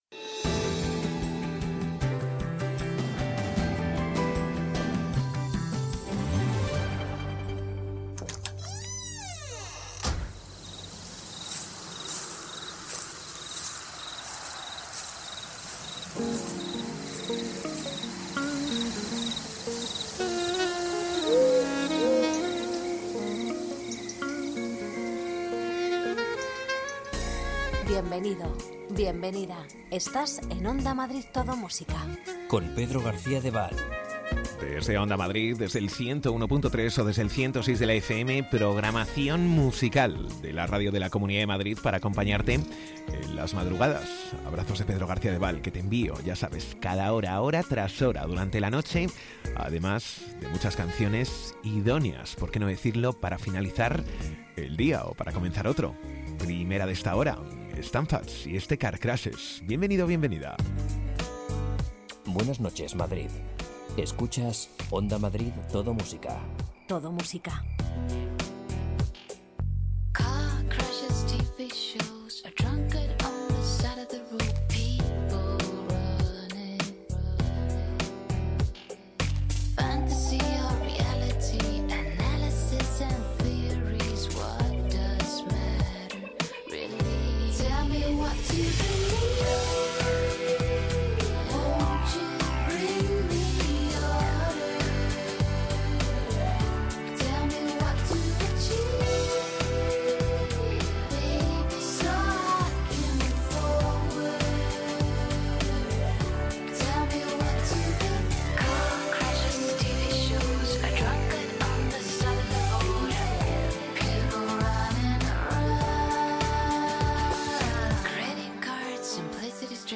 Ritmo tranquilo, sosegado, sin prisas... Las canciones que formaron parte de la banda sonora de tu vida tanto nacionales como internacionales las rescatamos del pasado durante la madrugada.